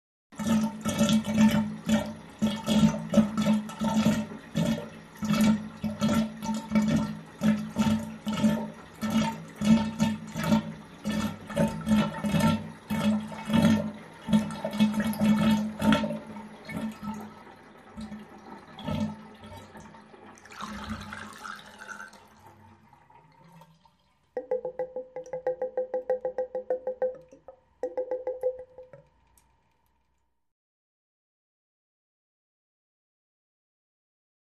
Drain, Large Tub; Water